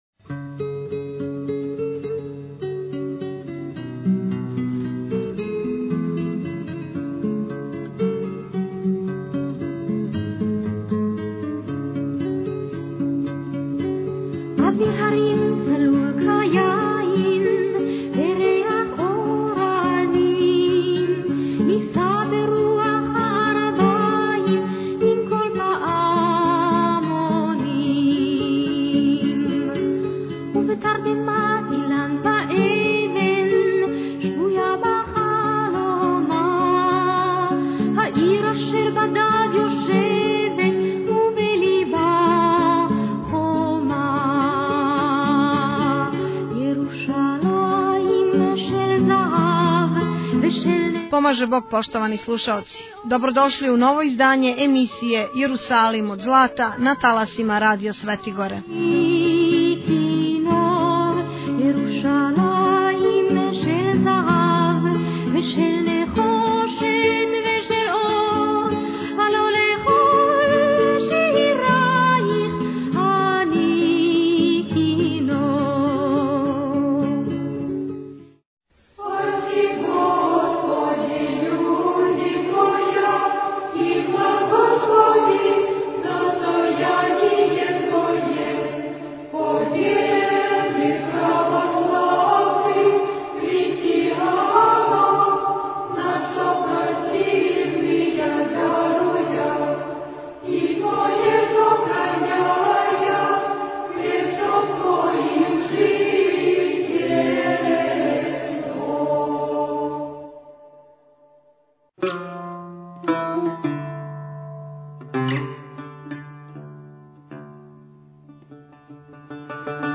у великој сали парохијског дома храма св. Александра Невског у Београду 14. маја 2016. године.